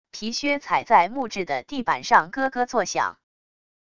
皮靴踩在木制的地板上咯咯作响wav音频